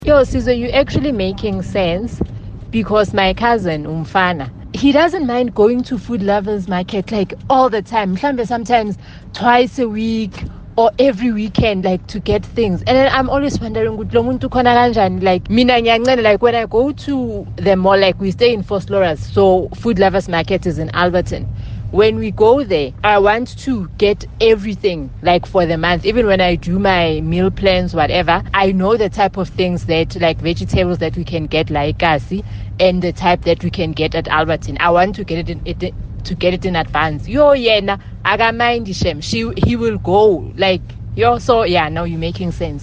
Here’s what Kaya Drive listeners had to share about their refrigerator habits: